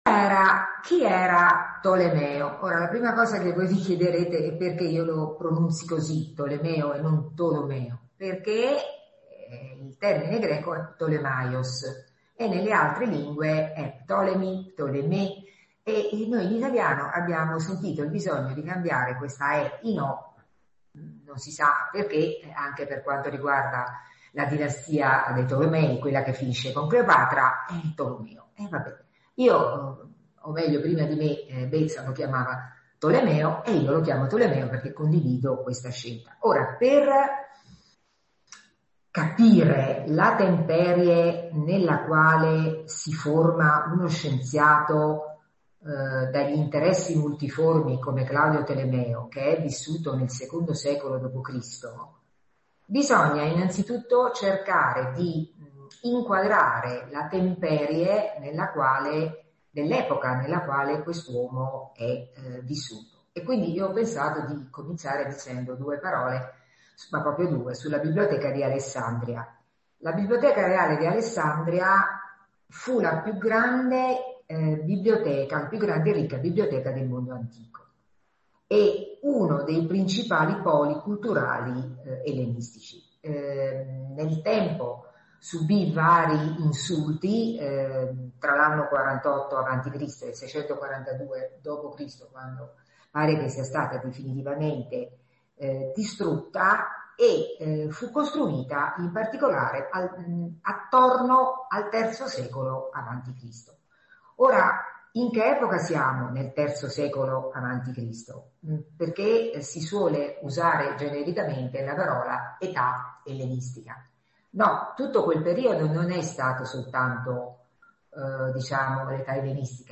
conversazione